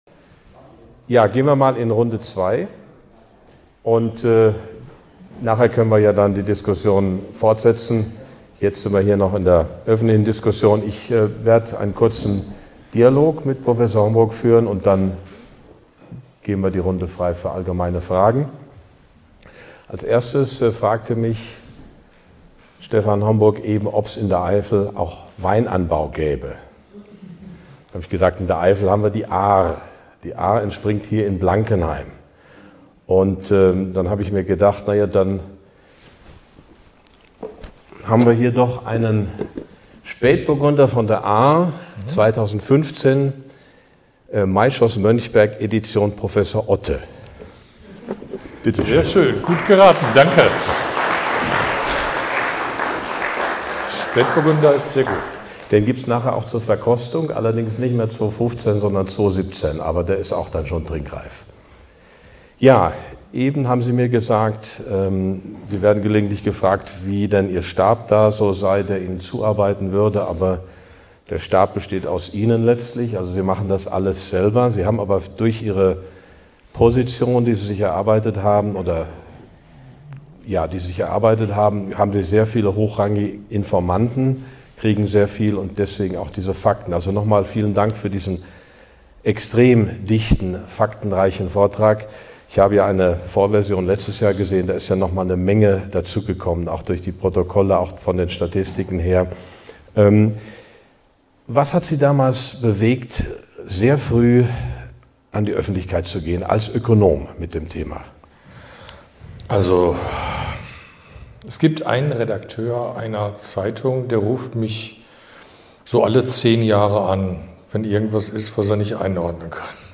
Beschreibung vor 8 Monaten In diesem aufschlussreichen Dialog analysiert Professor Stefan Homburg, Ökonom und Kritiker der ersten Stunde, die staatliche Corona-Politik. Auf Basis der RKI-Protokolle hinterfragt er die Verhältnismäßigkeit von Lockdowns und die Debatte um eine Impfpflicht und deckt Widersprüche in den offiziellen Begründungen auf. Das Gespräch beleuchtet zudem zentrale wirtschaftspolitische Fragen, wie die Auswirkungen der Handelspolitik von Donald Trump, und wirft einen Blick auf zukünftige Narrative, die unsere Gesellschaft prägen könnten.